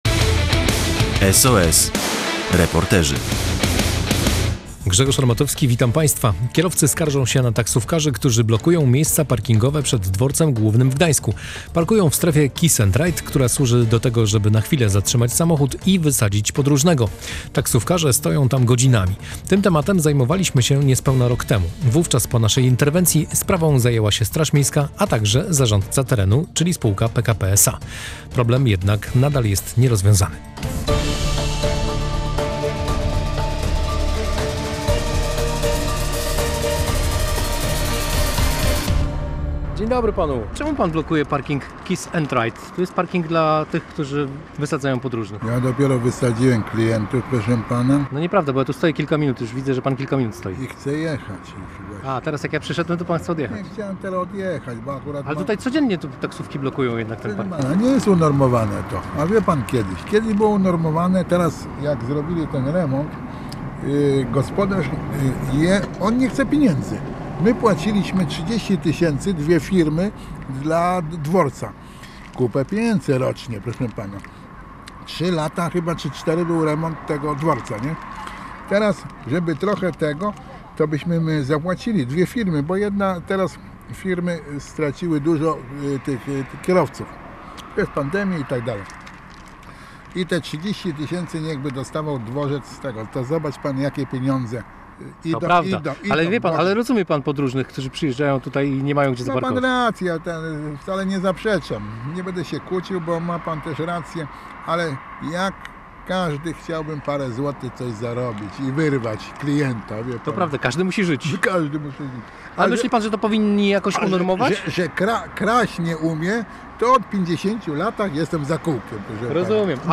Rozmawialiśmy z taksówkarzem, który zatrzymał się na parkingu „kiss & ride”.